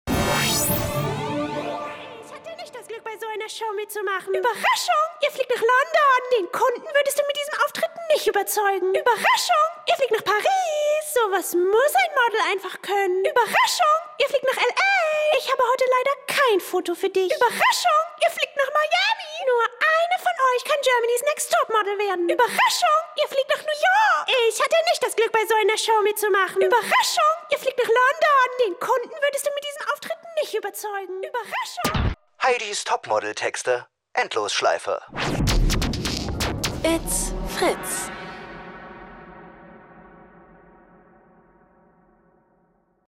Das Wichtigste aus 20 Staffeln GNTM in 30 Sekunden. Sound Memes - Das sind Memes für die Ohren.